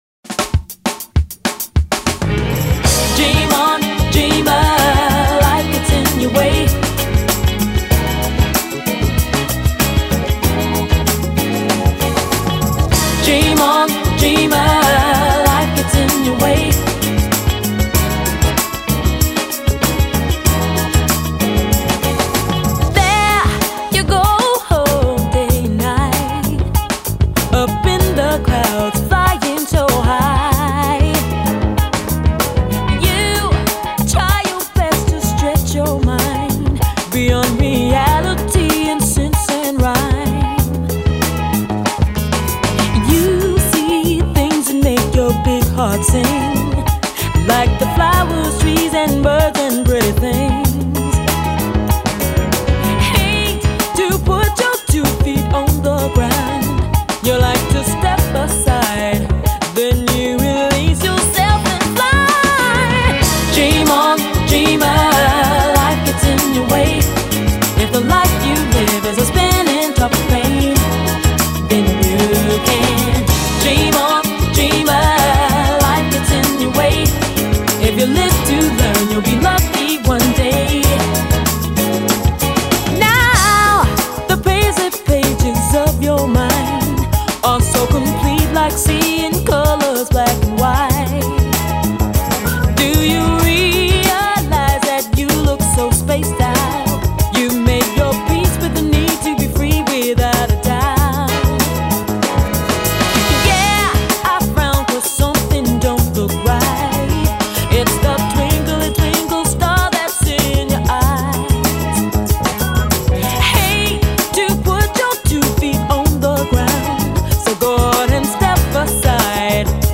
Jazz, Acid Jazz, Jazz Vocal